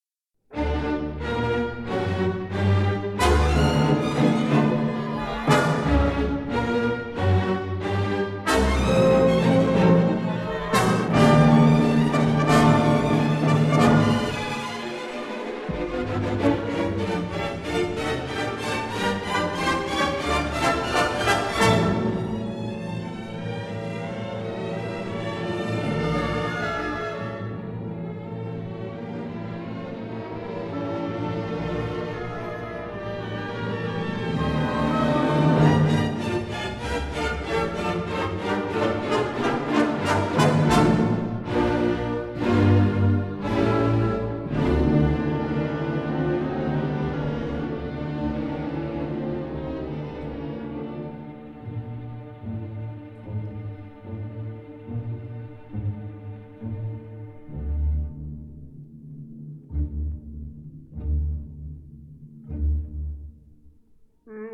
soprano
tenor
baritone
bass
Chorus and orchestra of the